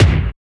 UGLY KICK.wav